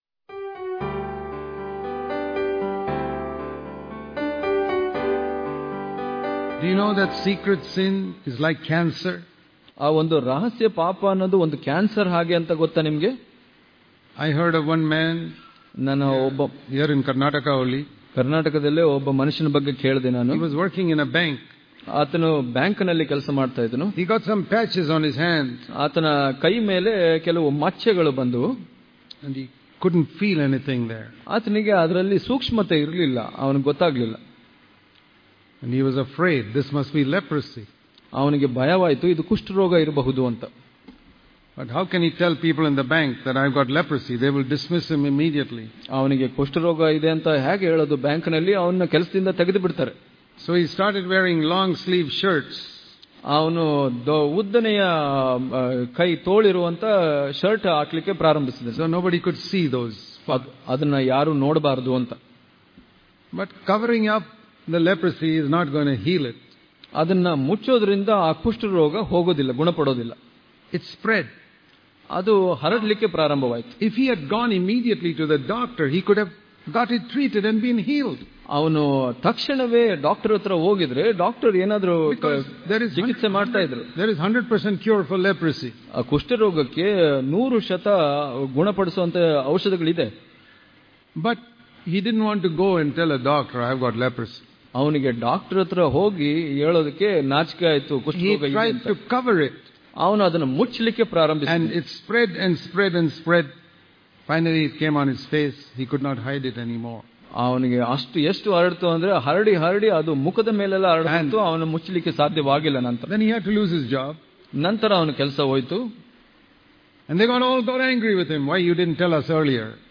November 28 | Kannada Daily Devotion | Avoid All Kinds Of Secret Sins Daily Devotions